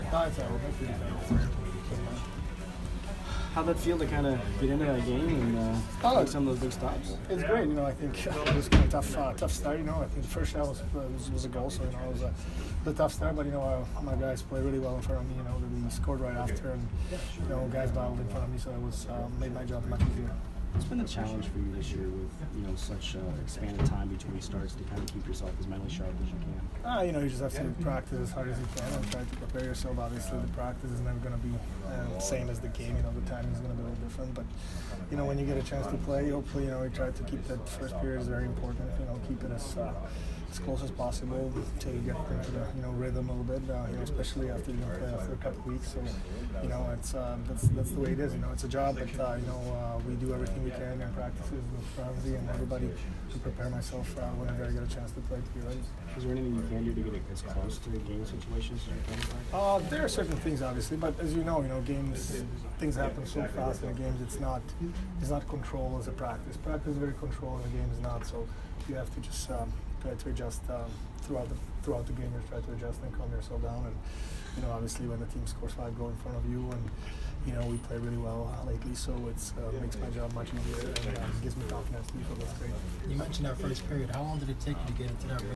Peter Budaj Post-Game 12/7